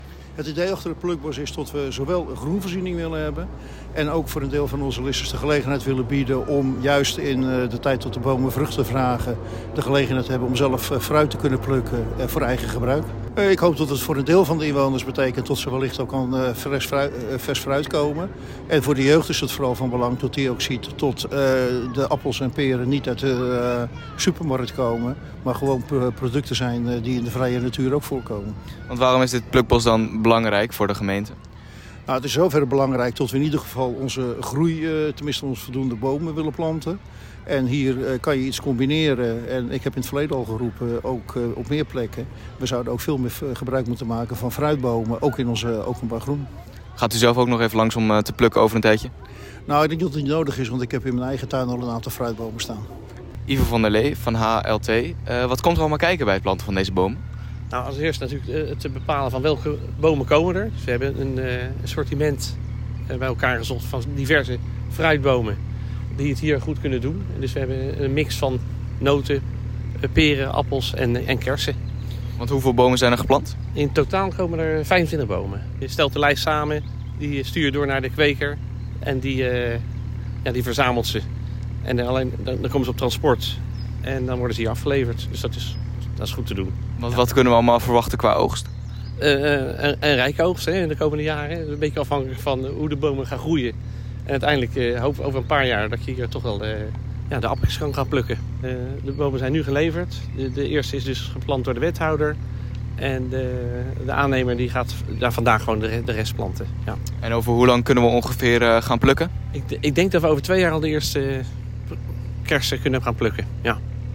Hieronder de radioreportage met als eerste wethouder Kees van der Zwet: